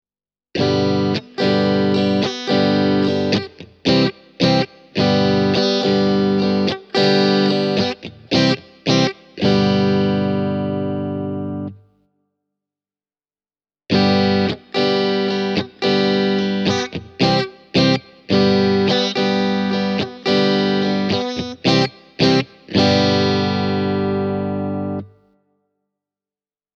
Äänitin koesarjan eri kitaroilla Blackstar HT-1R -putkikombolla niin, että jokaisessa klipissä ensimmäinen puolisko on äänitetty pelkästään Whirlwind-johdolla ja sitten toisessa on lisätty Spin X -johto signaalitiehen.
Strato Blackstarin kautta:
Spin X:n kanssa soundissa on vähemmän raapivaa terävyyttä diskantissa, enemmän avoimuutta ja kiiltoa ylä-middlen preesensalueella, sekä tiukempi ja tarkempi bassotoisto.
spin-x-cable-e28093-strat-amp.mp3